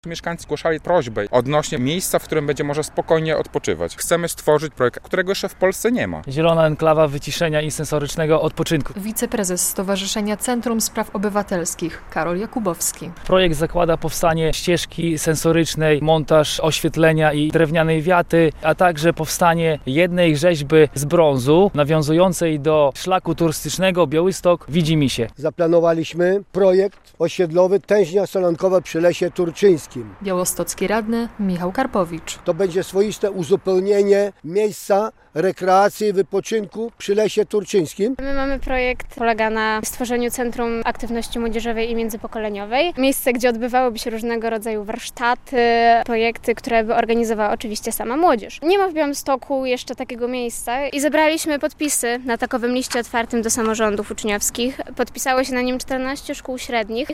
Na konferencji prasowej w czwartek (2.10) białostoccy radni, razem z przedstawicielami Centrum Spraw Obywatelskich i młodzieżową radą miasta, zachęcali mieszkańców do głosowania.